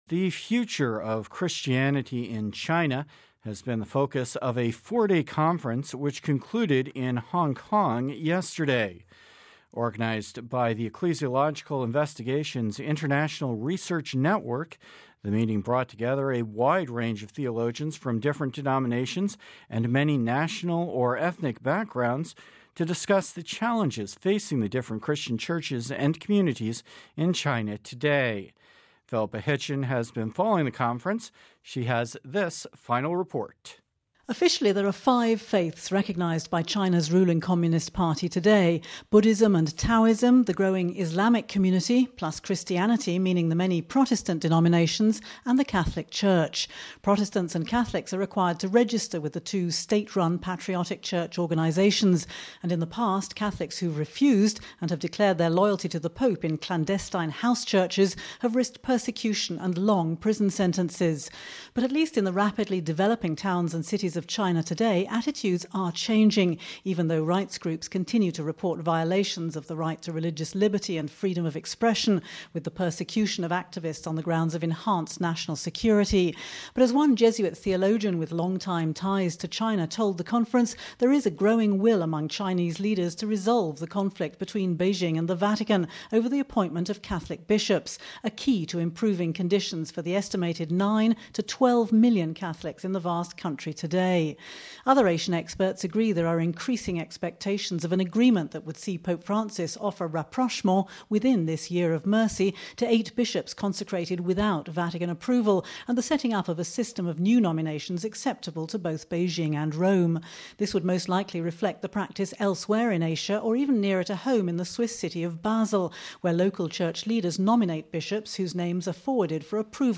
final report